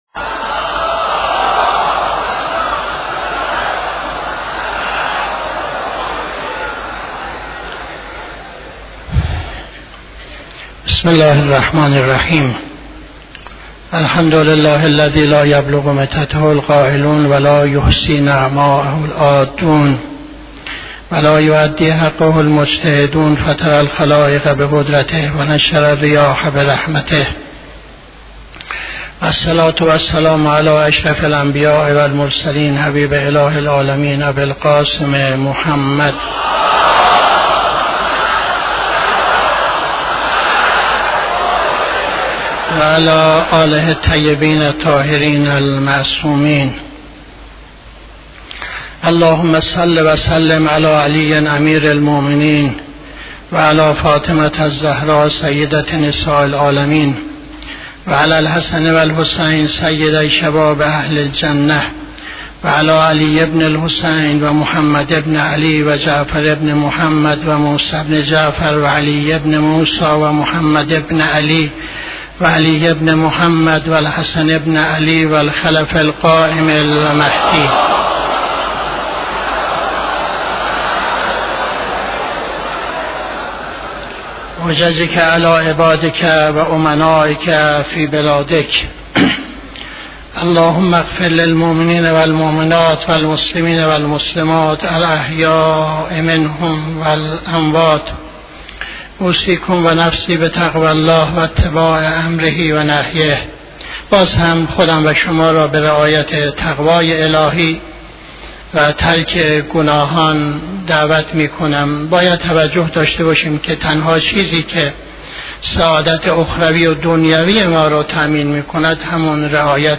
خطبه دوم نماز جمعه 02-01-81